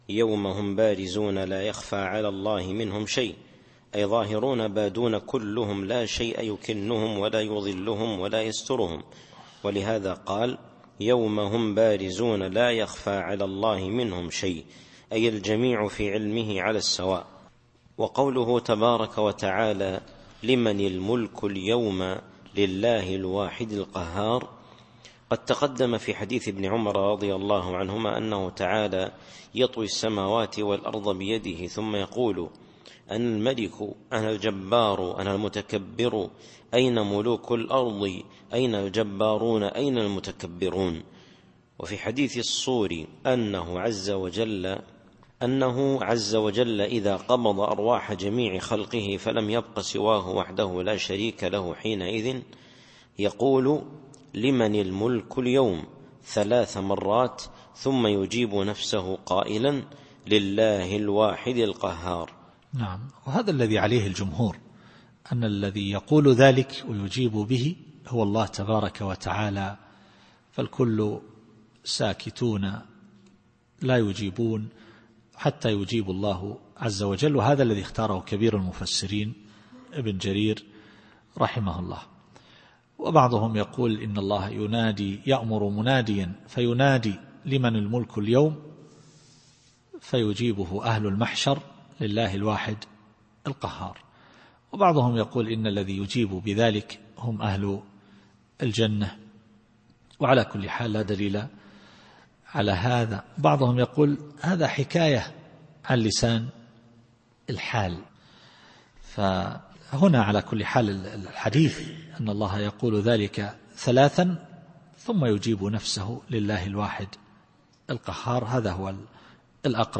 التفسير الصوتي [غافر / 16]